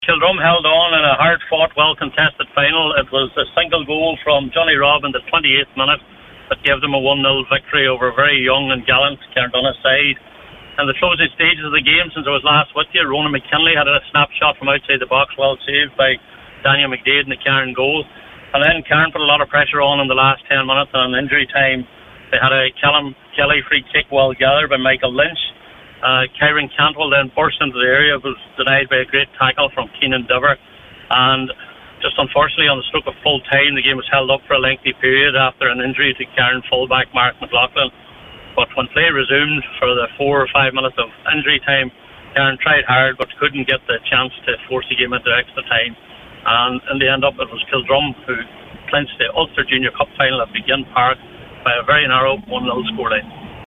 was live for Highland Radio Sport at full time…